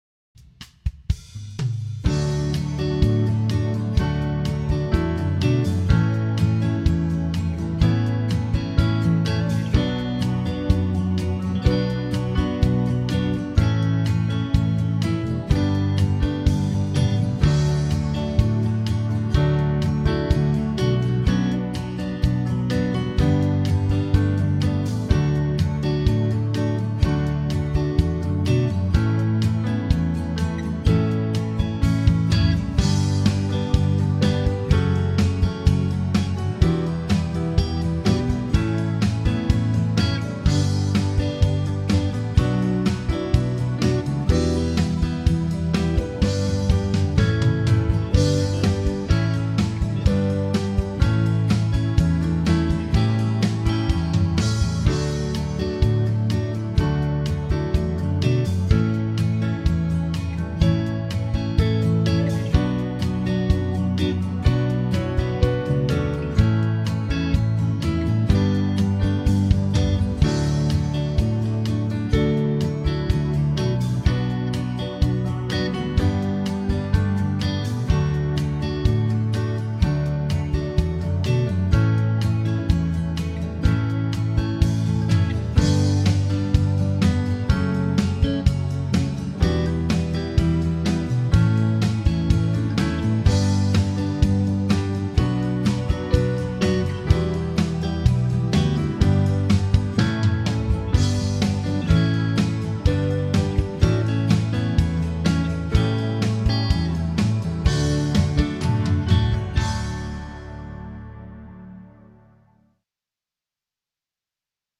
Home > Music > Rock > Bright > Smooth > Running